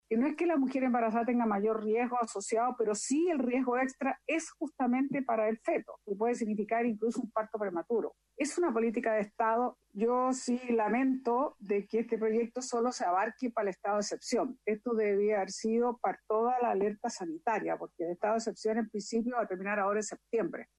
La senadora del PS, Isabel Allende, aseguró que posibilitar el trabajo a distancia para embarazadas debe ser una política de Estado. Eso sí, lamentó que la norma solo rija en ciertos casos.